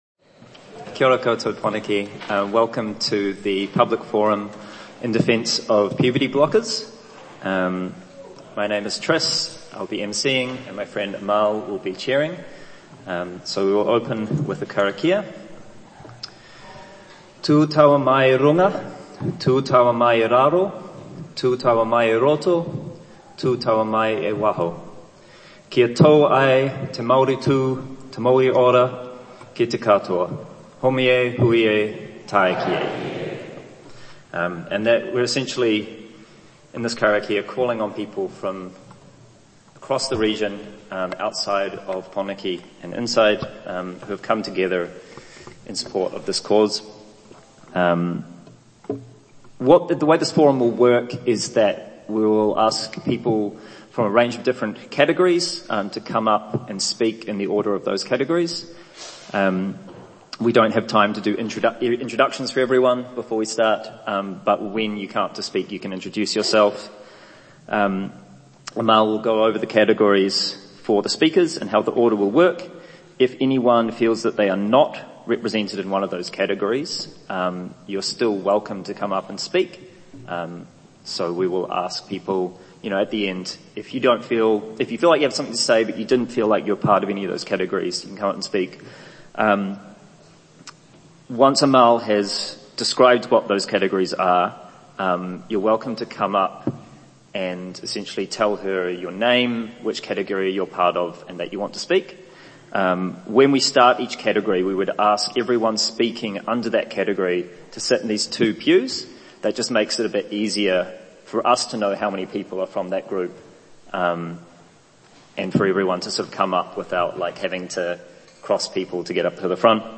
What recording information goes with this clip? Audio from public forum in defence of puberty blockers, held in St Peters Church, Willis Street, Wellington on Tuesday 9 December 2025